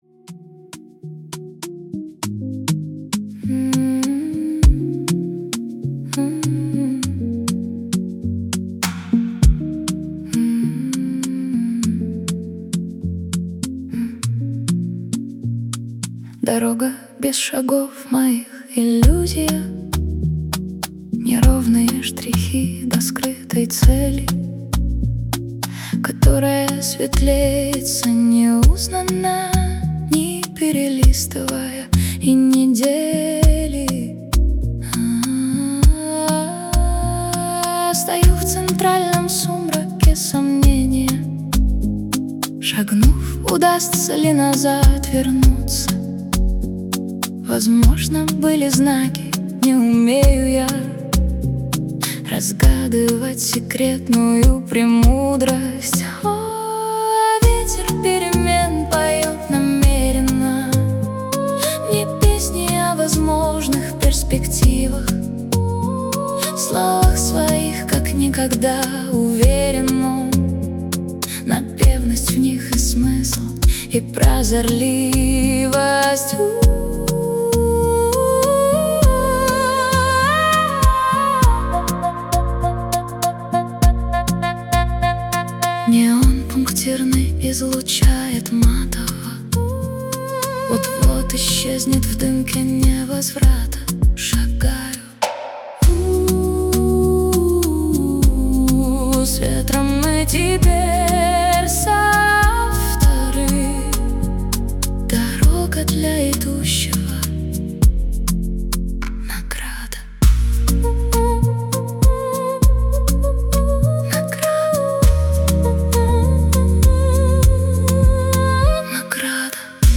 mp3,2911k] Авторская песня